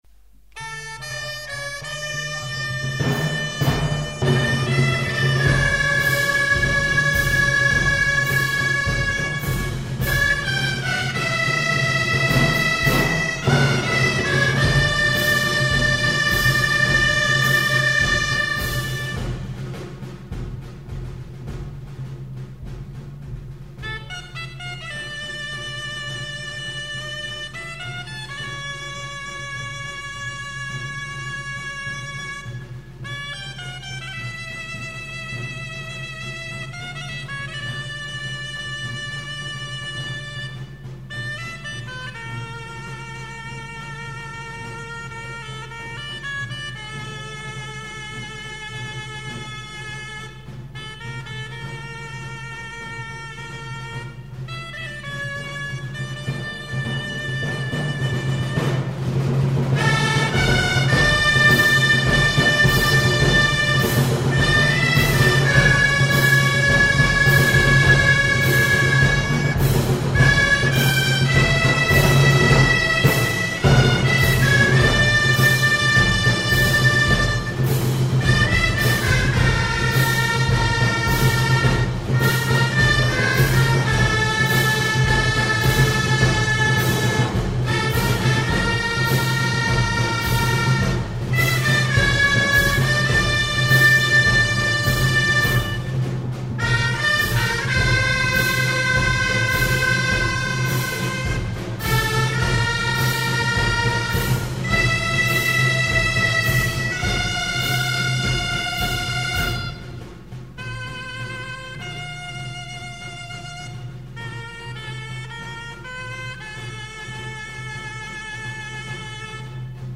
Gender: Moorish marches